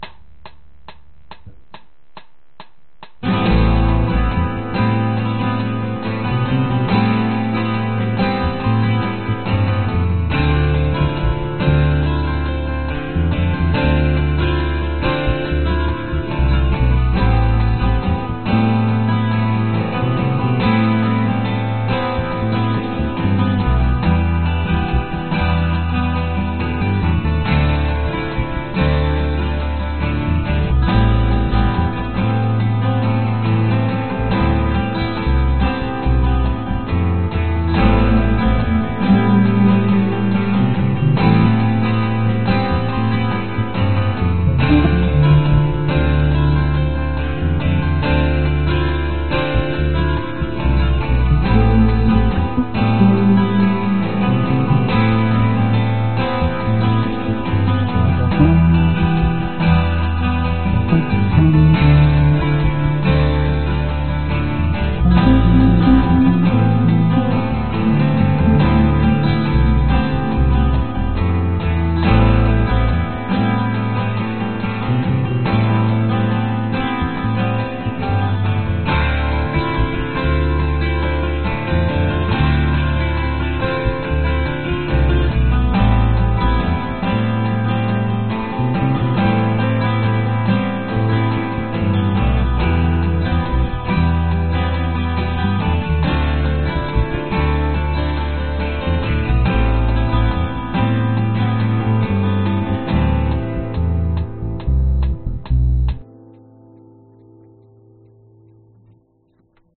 downtempo 贝斯 器乐 chill